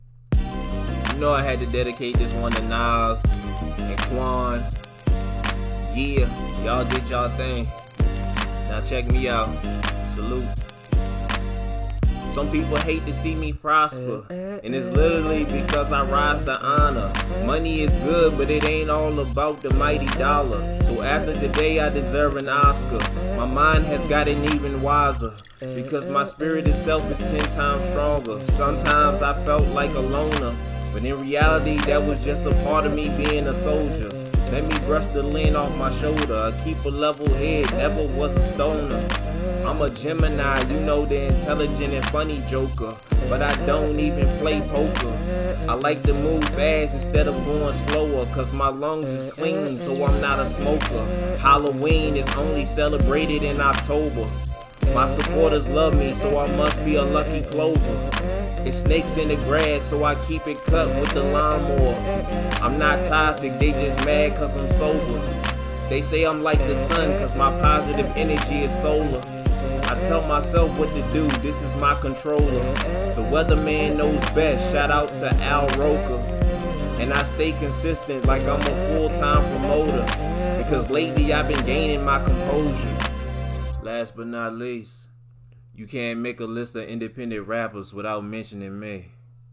Hiphop
Brand new freestyle